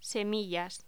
Locución: Semillas
locución
Sonidos: Voz humana